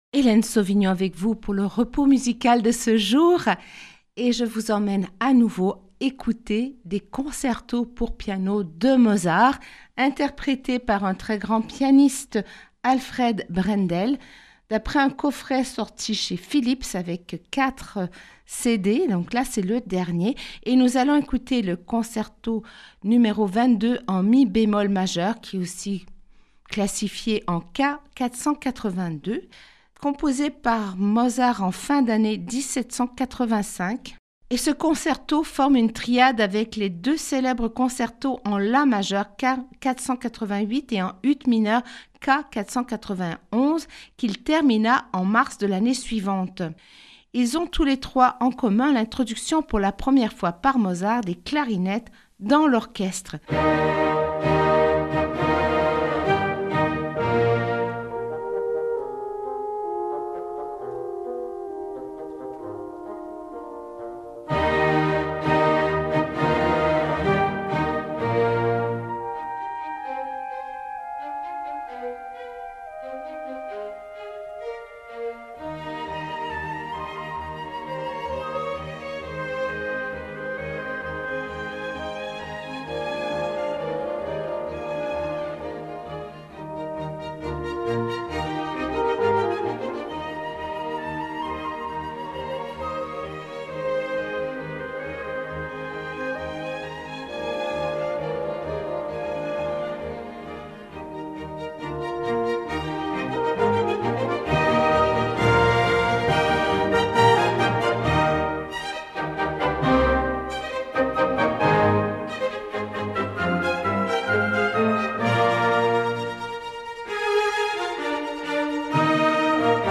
THE PIANO CONCEROS DE MOZART